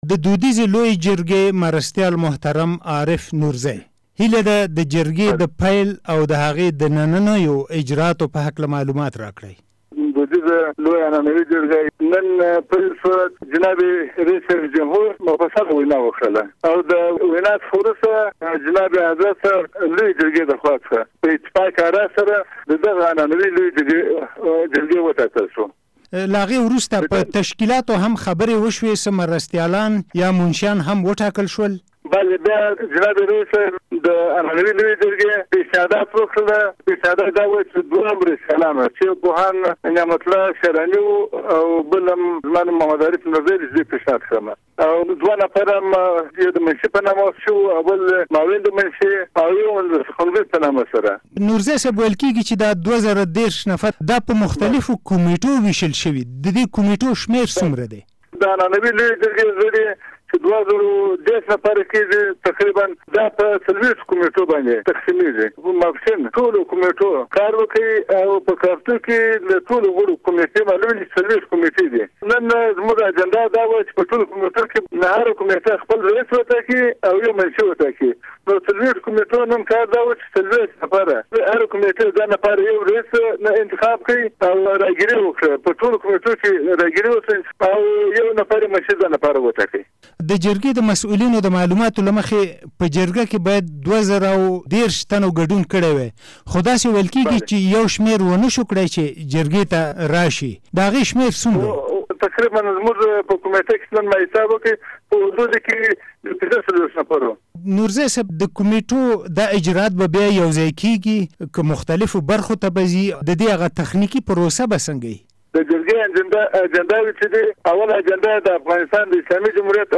له عارف نورزي سره مرکه